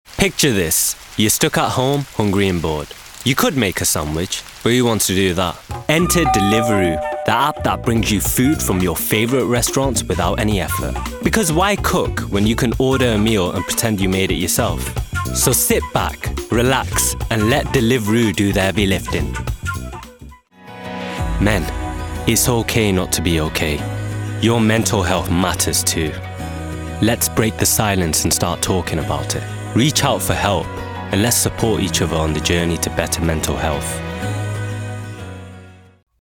Native Accent: Manchester Characteristics: Cool and Expressive Age